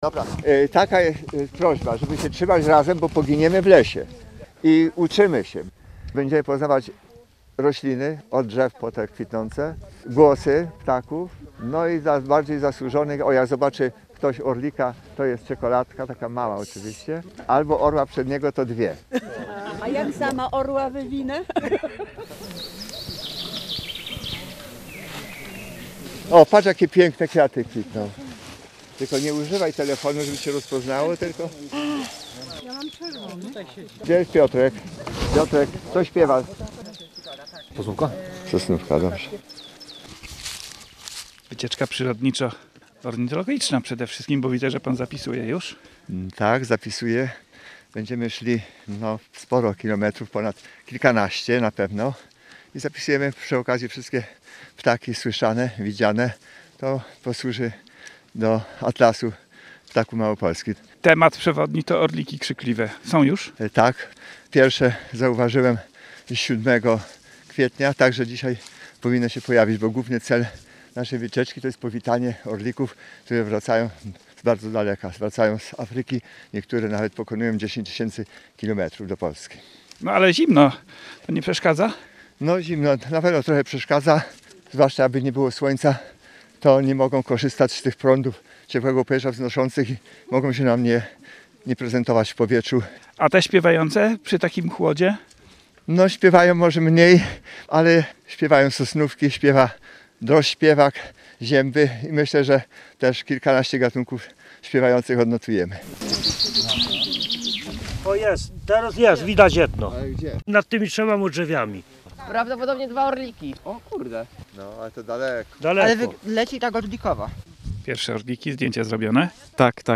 Relacje reporterskie • Orliki krzykliwe to symbol Magurskiego Parku Narodowego.